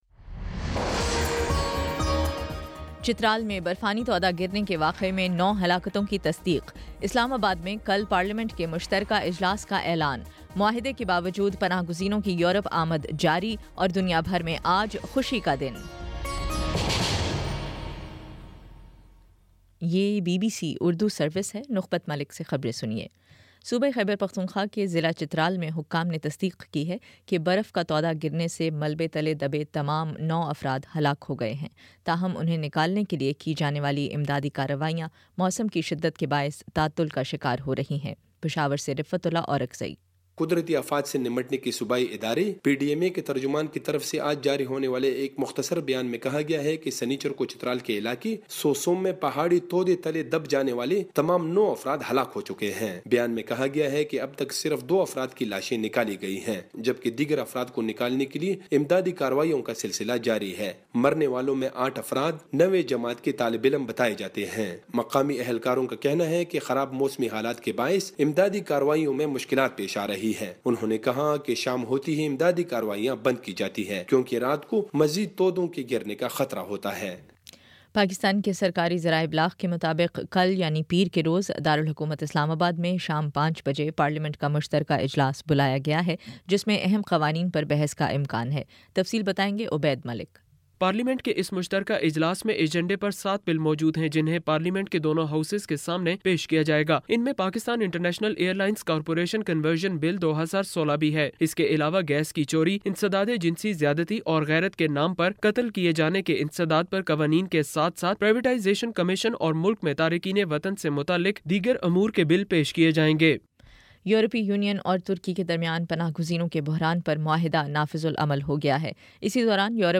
مارچ 20 : شام چھ بجے کا نیوز بُلیٹن
دس منٹ کا نیوز بُلیٹن روزانہ پاکستانی وقت کے مطابق شام 5 بجے، 6 بجے اور پھر 7 بجے۔مزید خبروں کے لیے وزٹ کریں